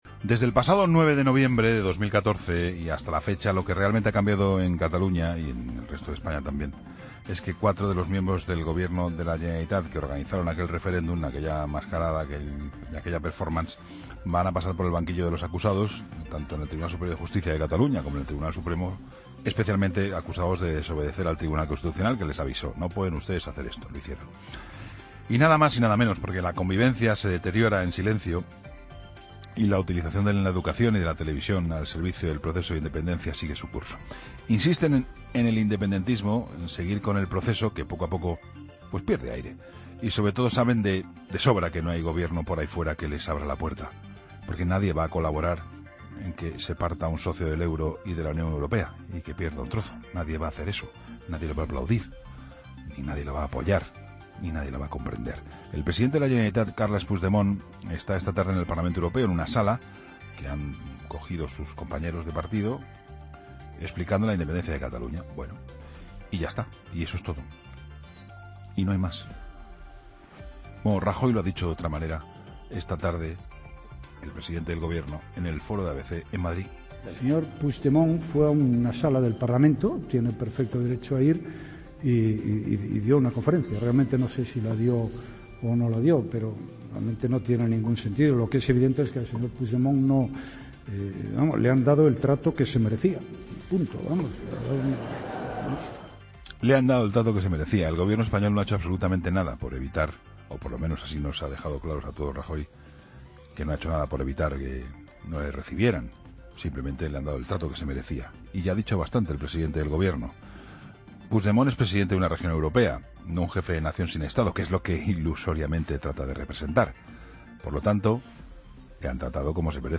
El análisis de Juan Pablo Colmenarejo en 'La Linterna' tras las palabras de Rajoy en el Foro ABC sobre Puigdemont al que “le iría mejor si buscara la mano tendida y no la radicalidad”